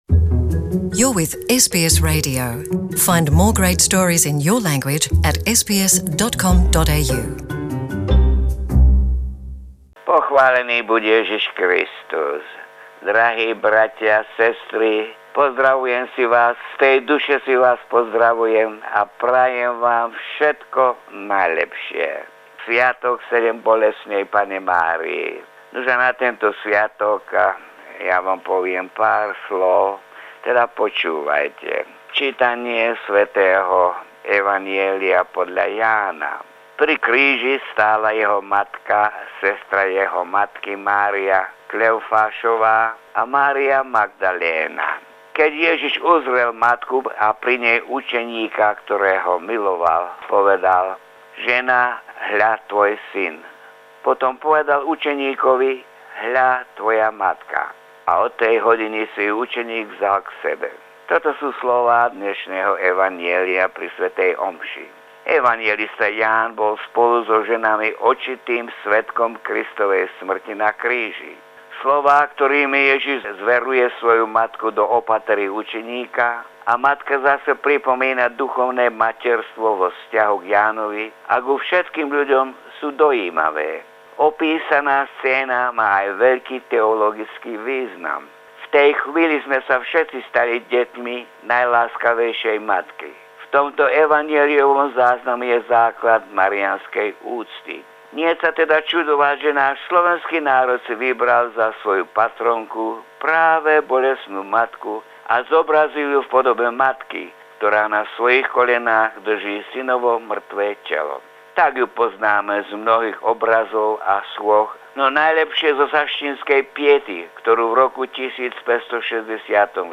Vzácna nahrávka príhovoru